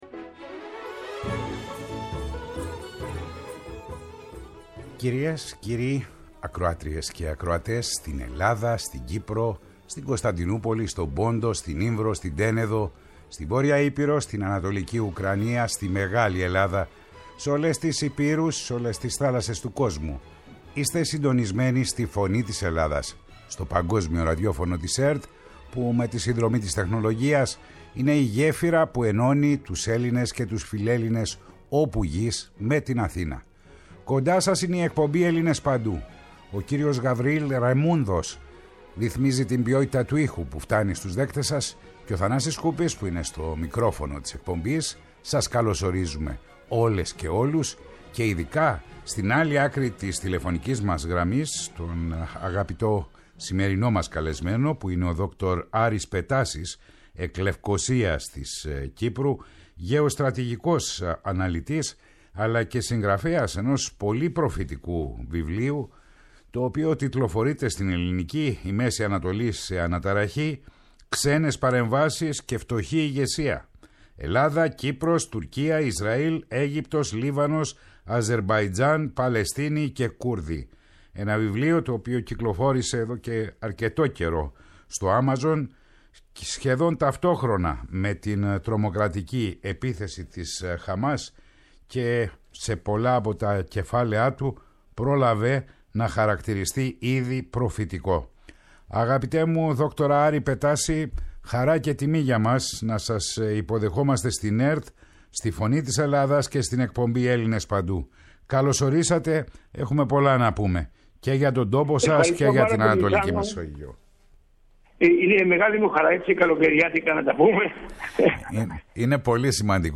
στους «Έλληνες παντού» Η ΦΩΝΗ ΤΗΣ ΕΛΛΑΔΑΣ Ελληνες Παντου ΣΥΝΕΝΤΕΥΞΕΙΣ Συνεντεύξεις